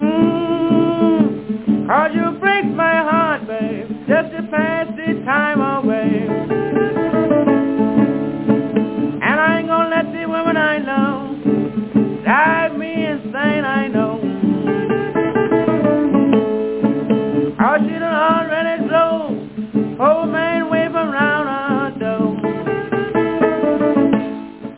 одноаккордные блюзы